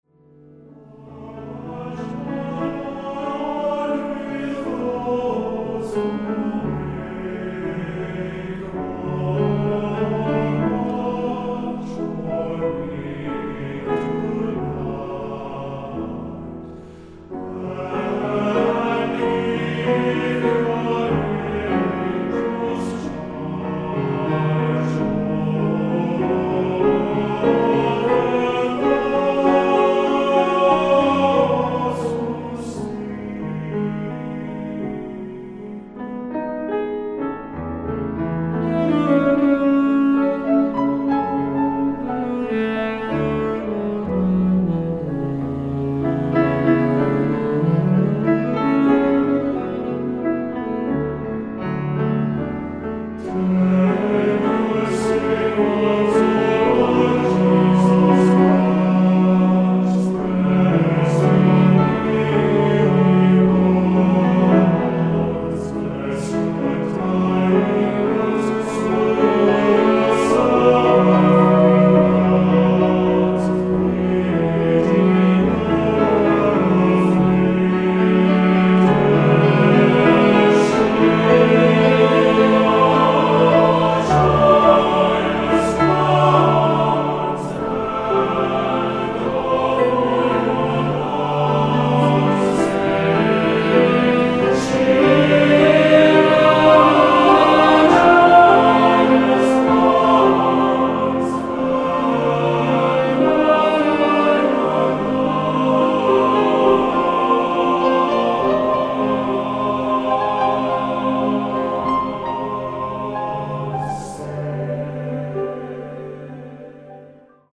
Choeur Mixte (SATB)